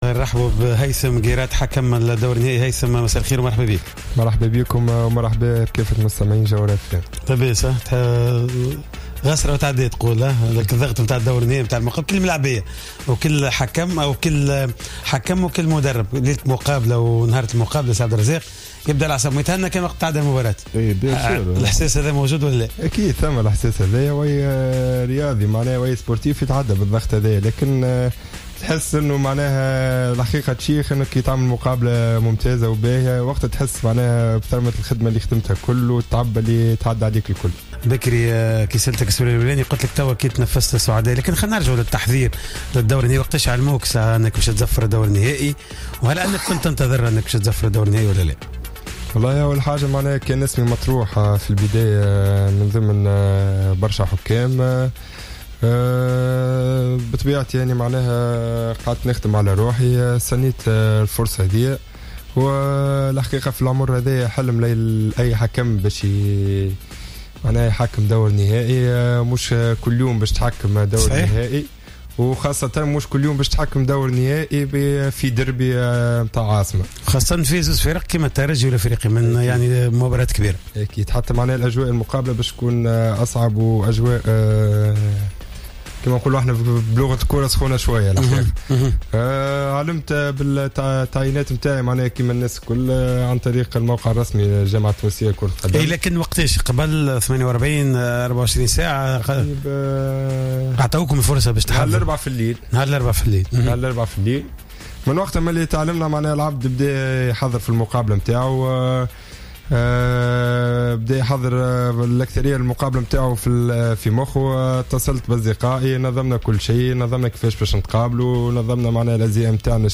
ضيف برنامج "راديو سبور"